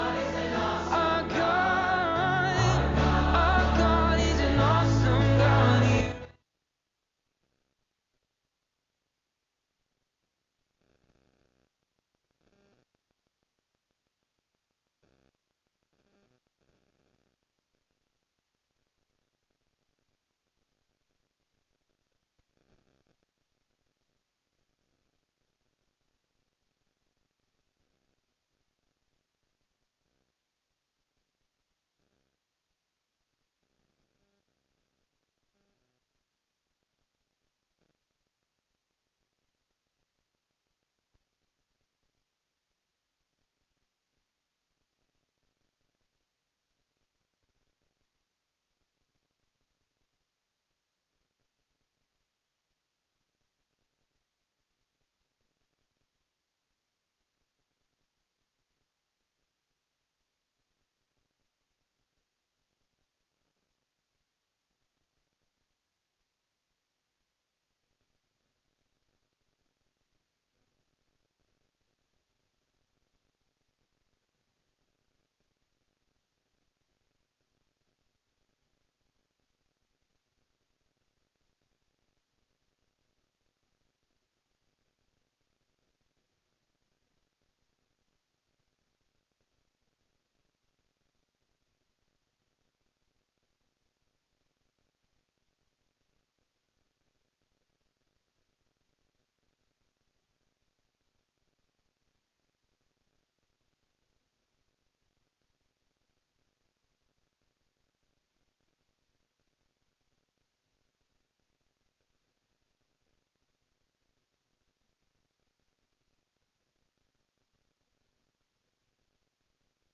SERMON DESCRIPTION The ministry of John the Baptist marked the transition from corporate religious practices to individual personal faith.